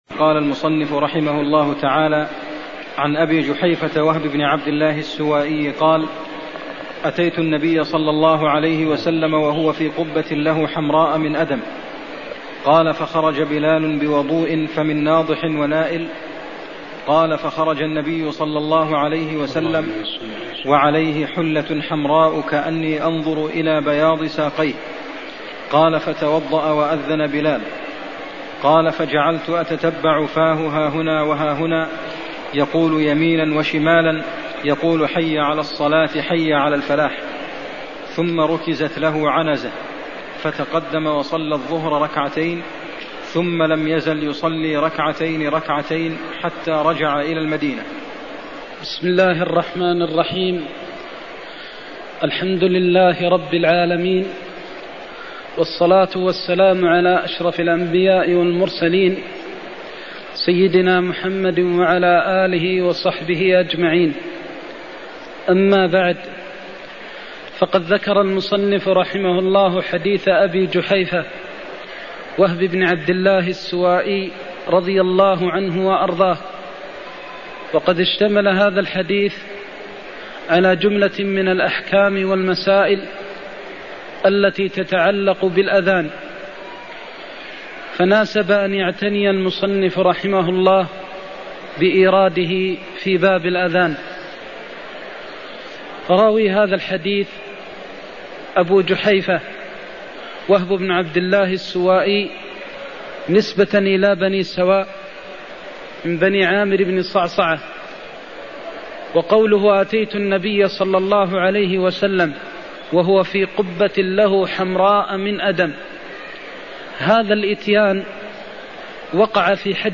المكان: المسجد النبوي الشيخ: فضيلة الشيخ د. محمد بن محمد المختار فضيلة الشيخ د. محمد بن محمد المختار قال أتيت النبي صلى الله عليه وسلم وهو في قبة (61)